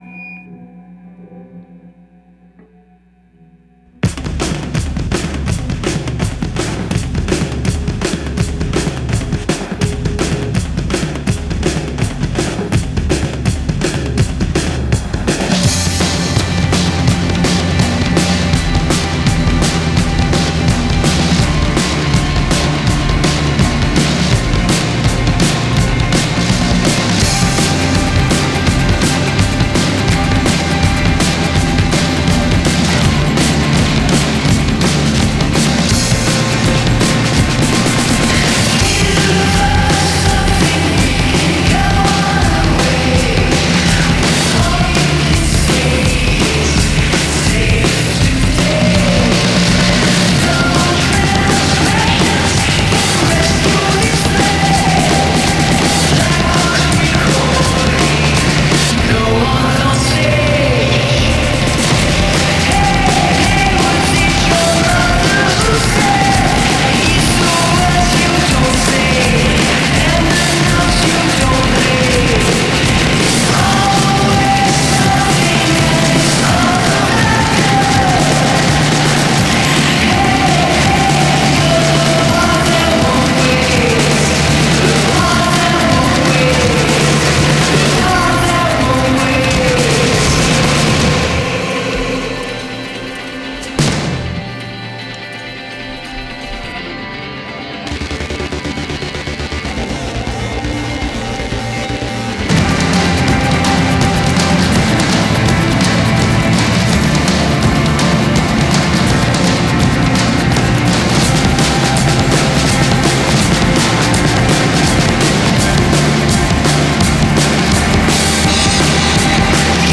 the great drumming